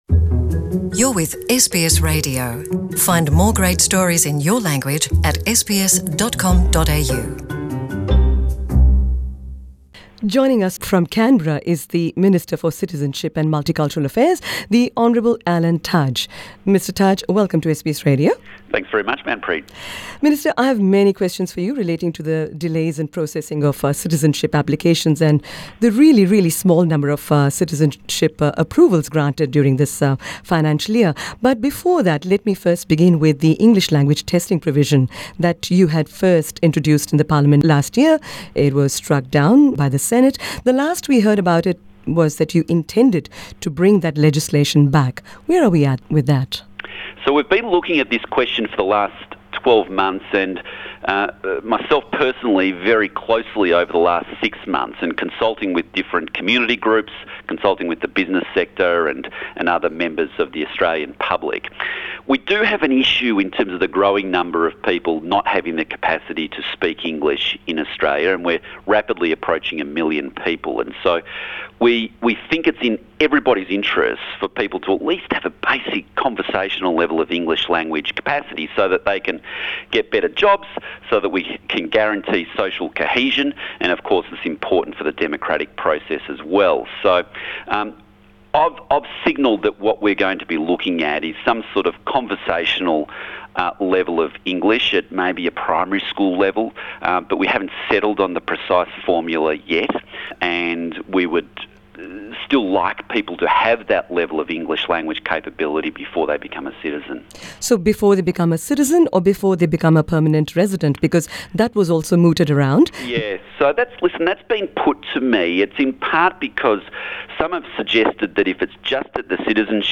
In an exclusive interview with SBS Punjabi, the Minister for Citizenship and Multicultural Affairs Alan Tudge said that the Turnbull government has introduced necessary changes in order to attract the 'best and the brightest' migrants to Australia.